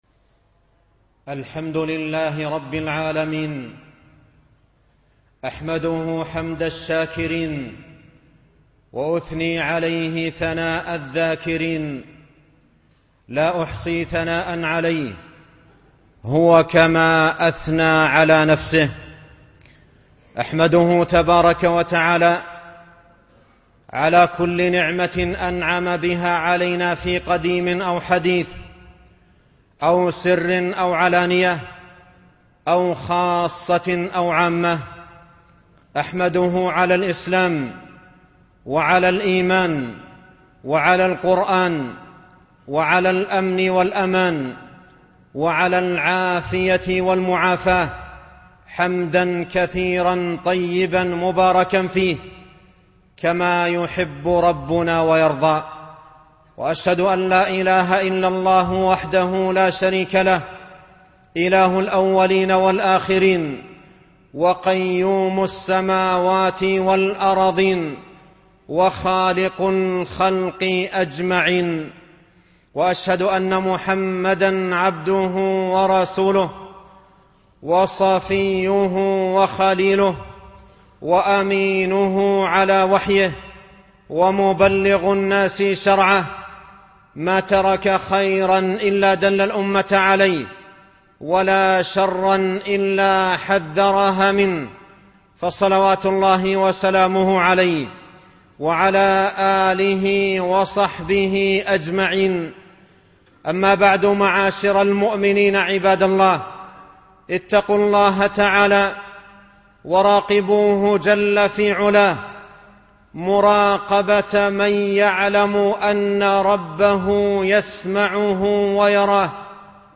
فضل العلم والعلماء - خطبة